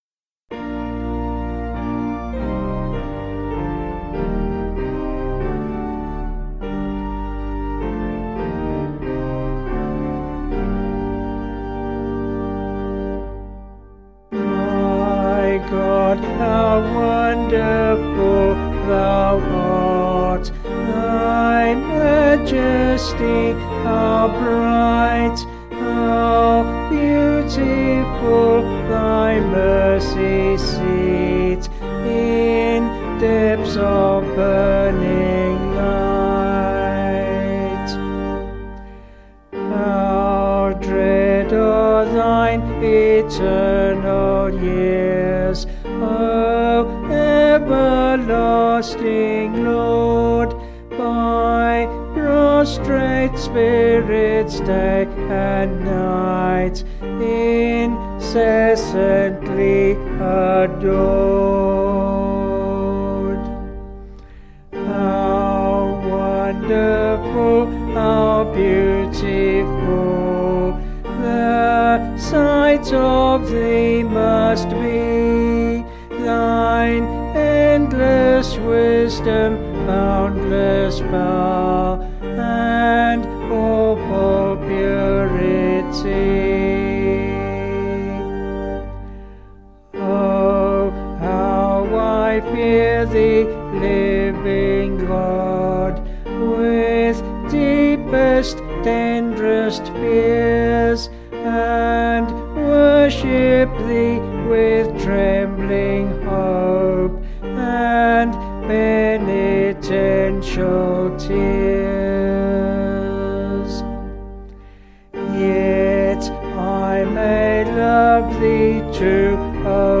Vocals and Organ   265.2kb Sung Lyrics